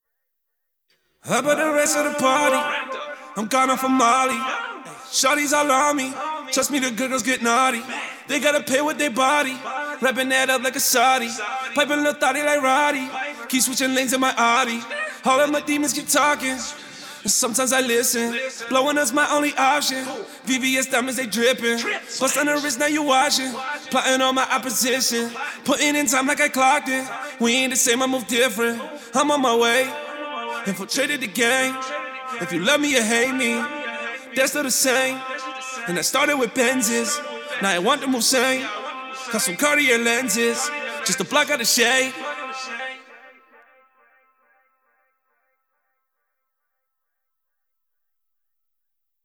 POP SMTHN RAP VERSE FULL MIX 38BPM.wav